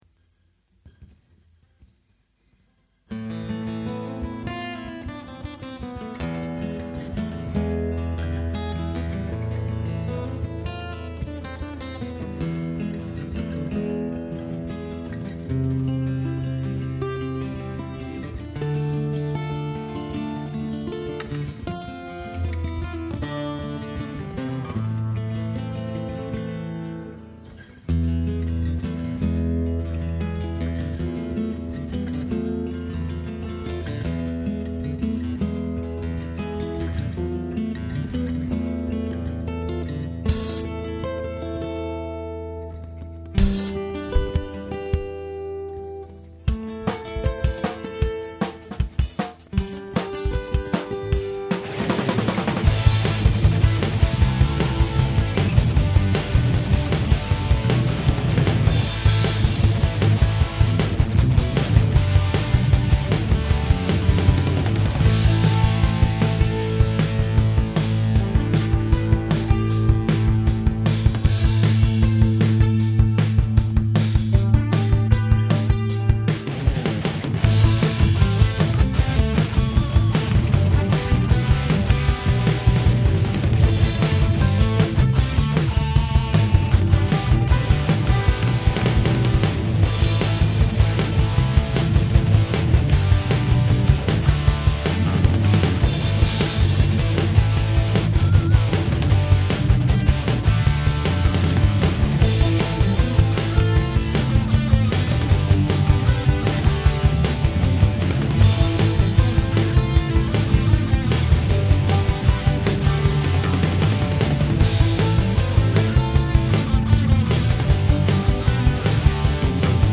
Klassischer Rock, für meine vorherige Band gemacht, und da ich nicht singen kann, ist es noch ohne Text und vocals, sorry.